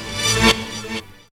68_25_stabhit-A.wav